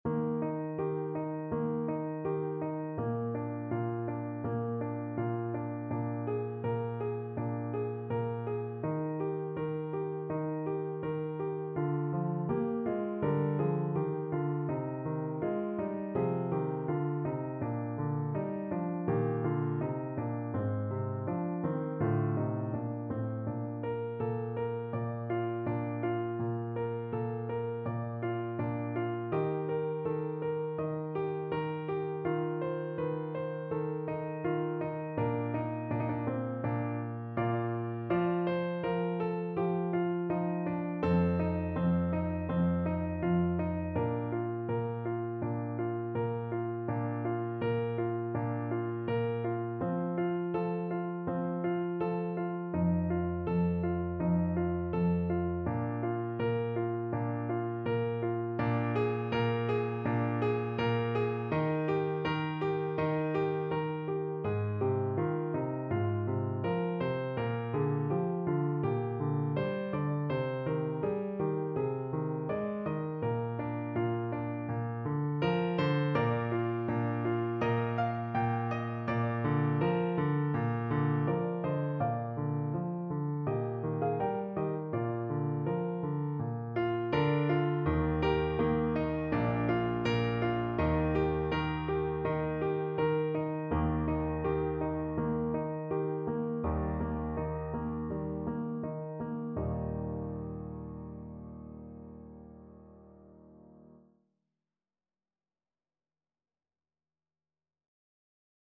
Vivaldi: Zima (na skrzypce i fortepian)
Symulacja akompaniamentu